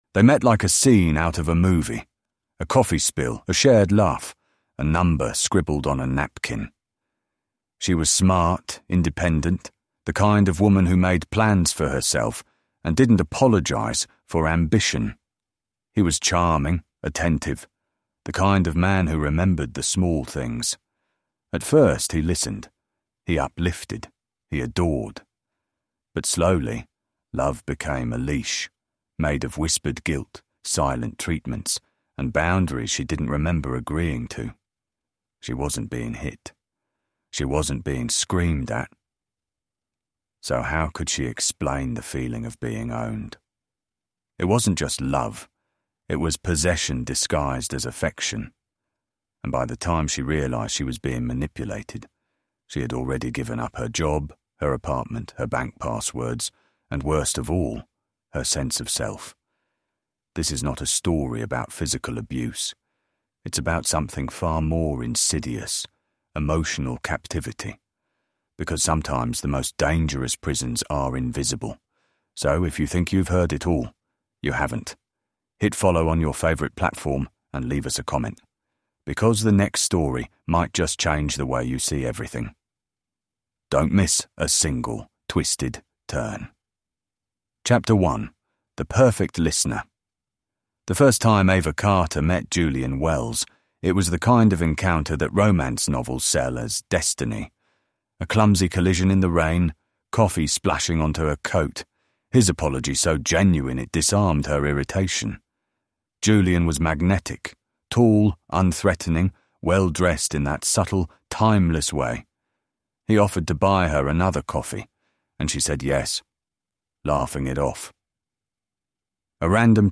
This cinematic true crime thriller follows Ava, a brilliant woman whose seemingly perfect boyfriend slowly dismantles her independence through subtle manipulation, psychological coercion, and weaponized affection.